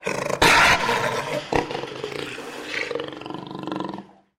Звуки ягуара
Рычание древнего самца